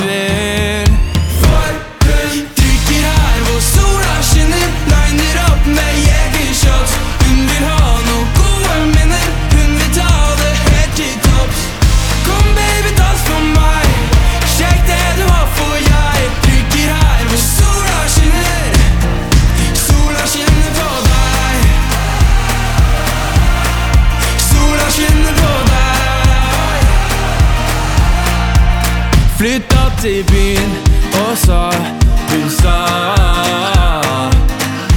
Скачать припев
2025-05-09 Жанр: Поп музыка Длительность